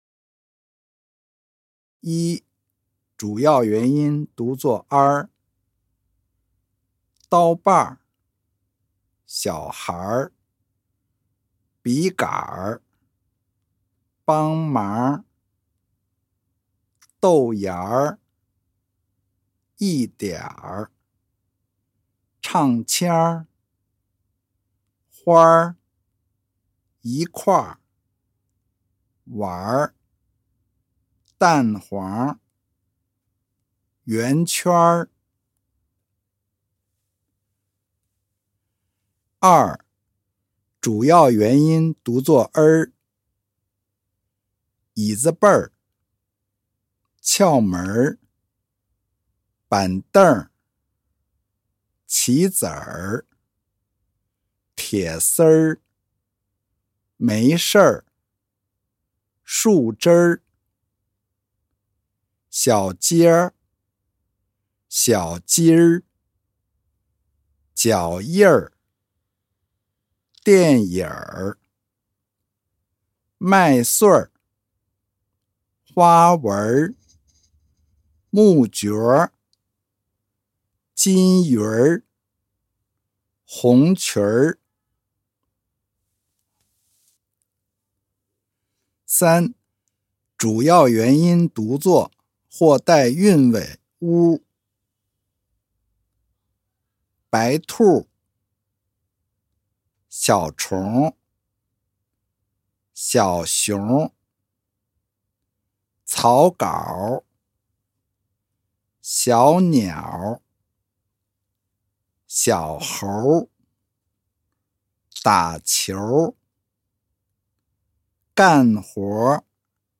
1.第十四讲儿化发音例词-男声